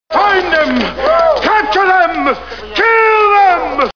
Category: Comedians   Right: Personal
Tags: Dom DeLuise Dom DeLuise clips Dom DeLuise sounds Dom DeLuise audio clips Comedian